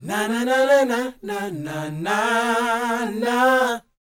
NA-NA A#B -L.wav